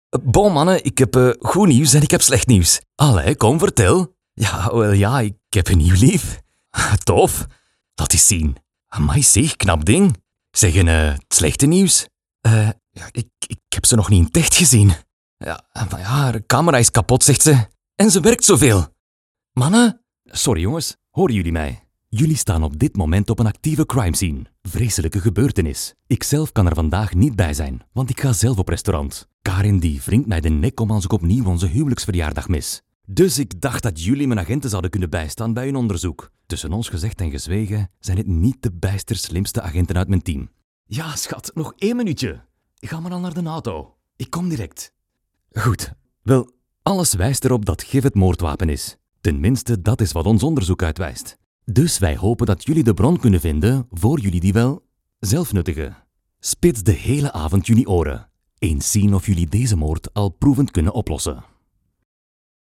20s – 30s. Male. Flemish. Spanish. Studio.
Flemish Spanish Home Studio Read English Home Studio Neumann TLM 102 and the Audio-Technica 2035, Audient ID4 MKII interface & Presonus Studio One Software. Acoustically treated room with sound-insulating panels.